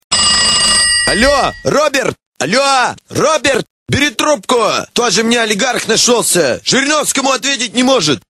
Главная » Рингтоны » Рингтоны пародии